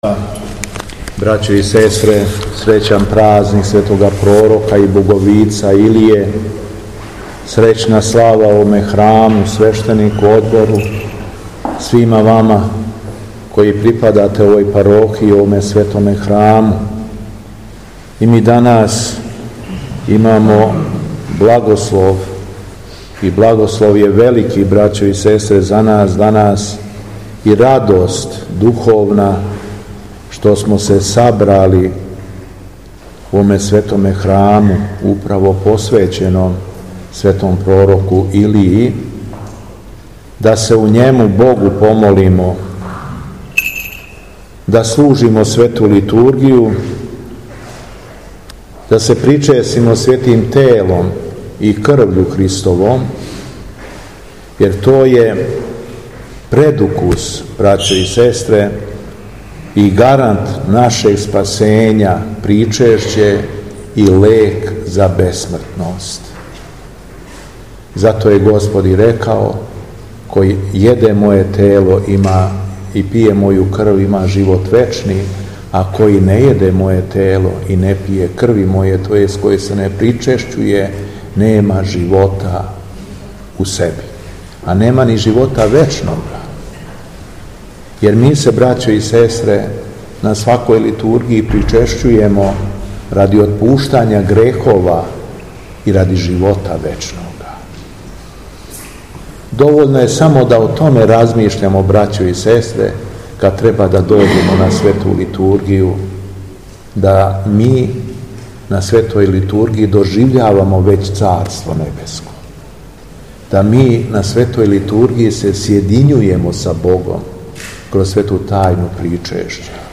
Беседа Његовог Преосвештенства Епископа шумадијског г. Јована
Након прочитаног Јеванђеља владика се обратио верном народу Богонадахнутом беседом.